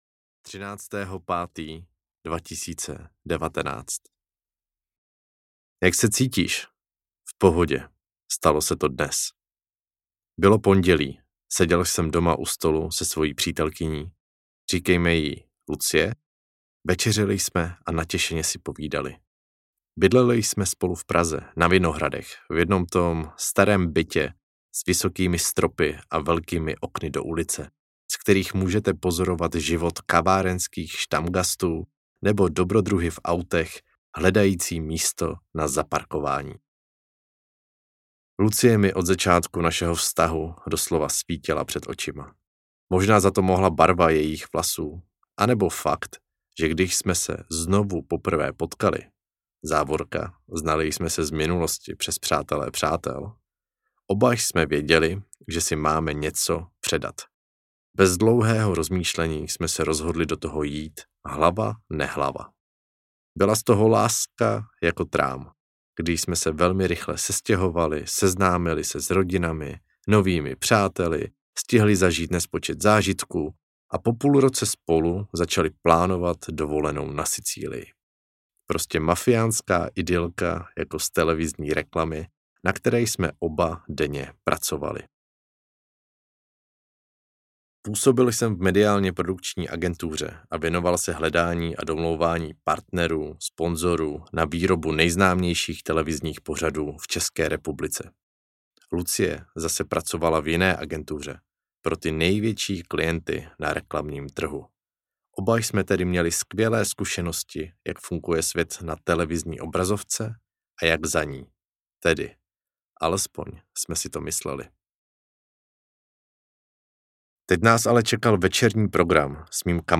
Dovol si TO audiokniha
Ukázka z knihy